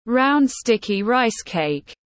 Bánh giầy tiếng anh gọi là round sticky rice cake, phiên âm tiếng anh đọc là /raʊnd ˈstɪk.i raɪs keɪk/
Round sticky rice cake /raʊnd ˈstɪk.i raɪs keɪk/